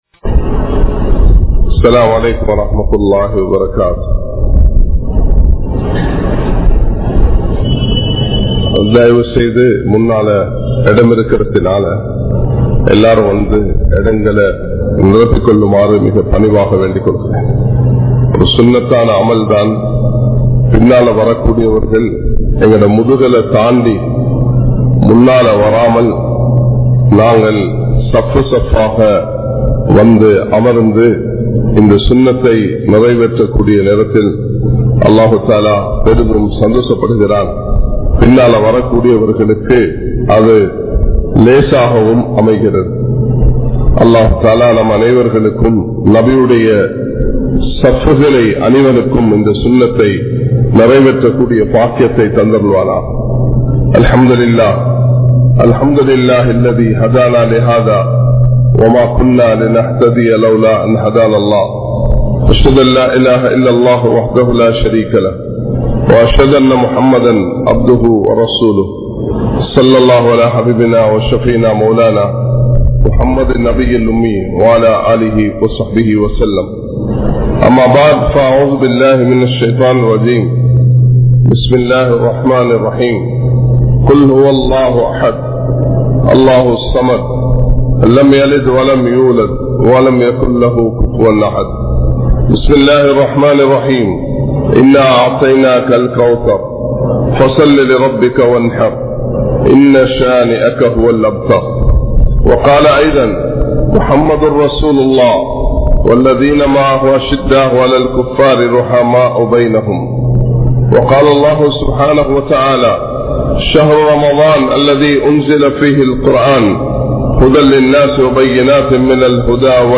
Manithaa! Peumai Adikkaathe (மனிதா! பெருமை அடிக்காதே) | Audio Bayans | All Ceylon Muslim Youth Community | Addalaichenai
Majma Ul Khairah Jumua Masjith (Nimal Road)